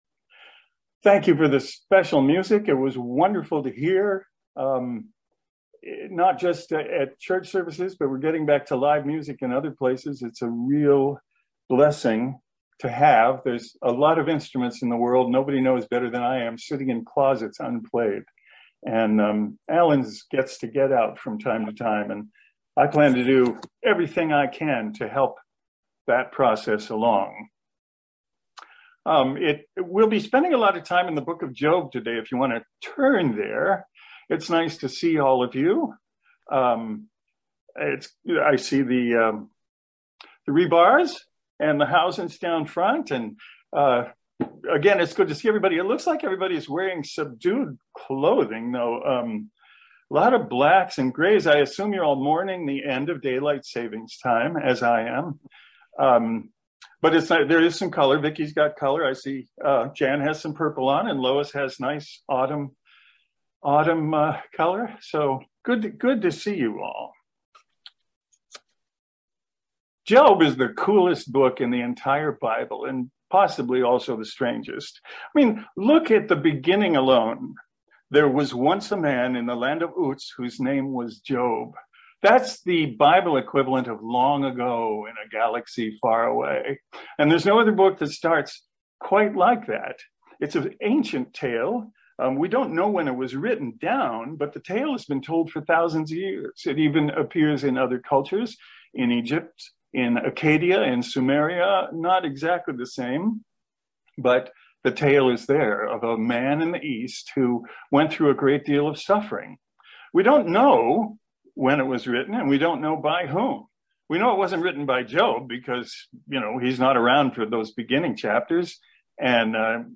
This Sermon was recorded via Zoom.